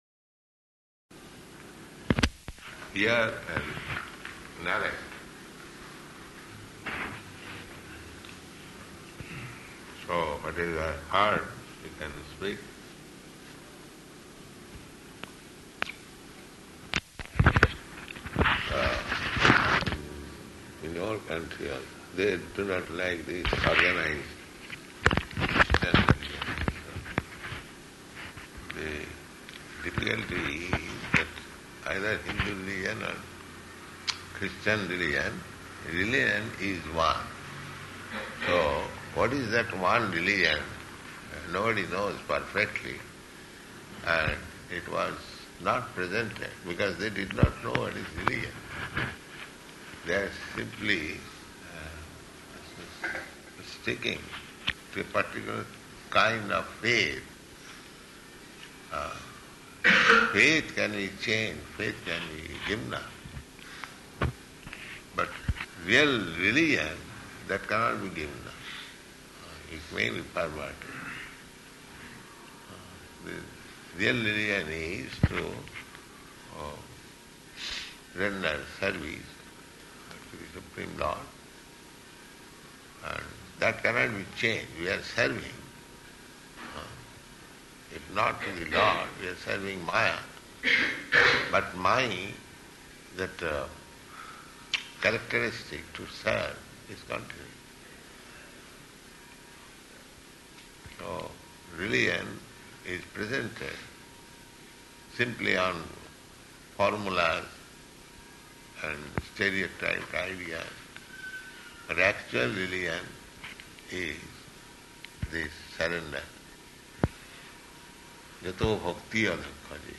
Room Conversation
Room Conversation --:-- --:-- Type: Conversation Dated: December 12th 1971 Location: Delhi Audio file: 711212R1-DELHI.mp3 Prabhupāda: Hear and narrate.